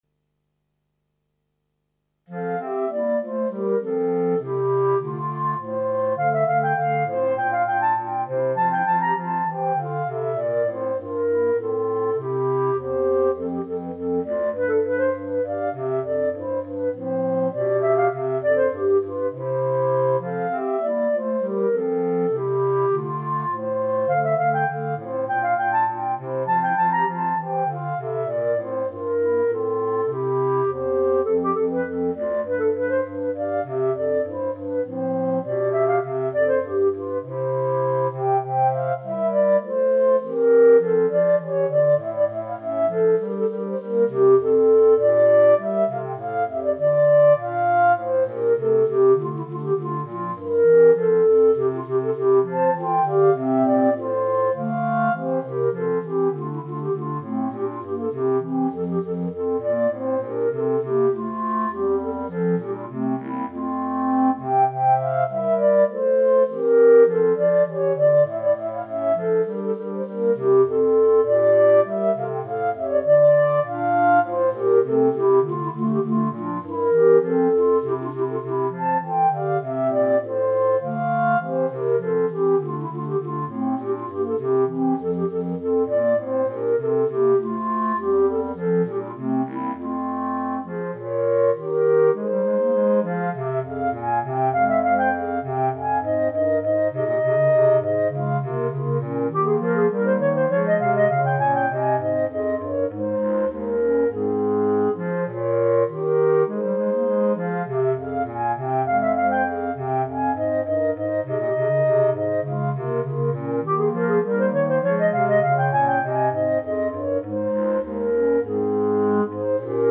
Clarinet Quartet
Instrumentation: 3 Clarinet, Bass Clarinet